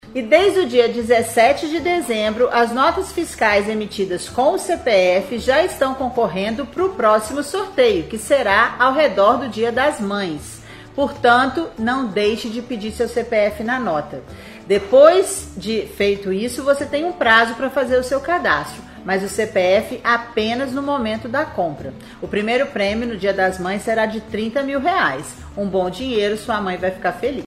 Conforme a secretária, o próximo sorteio está previsto para ocorrer no Dia das Mães.